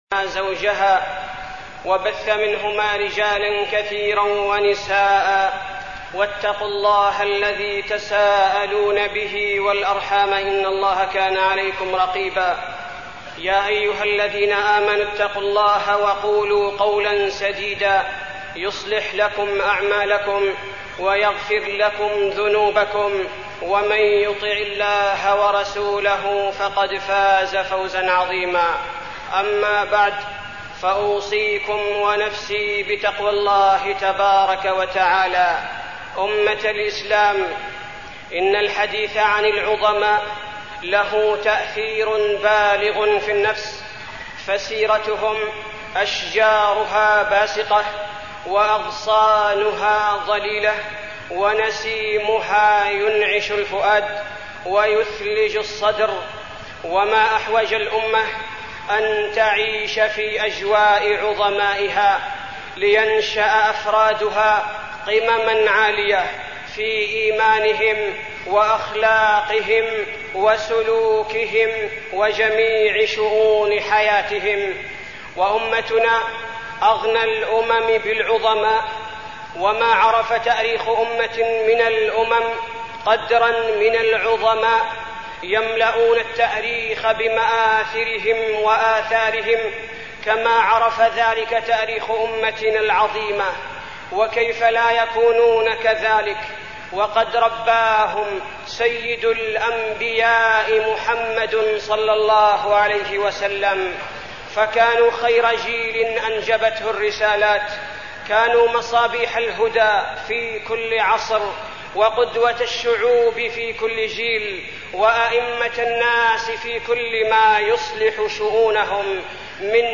تاريخ النشر ٢٣ ربيع الثاني ١٤١٧ هـ المكان: المسجد النبوي الشيخ: فضيلة الشيخ عبدالباري الثبيتي فضيلة الشيخ عبدالباري الثبيتي أبوبكر الصديق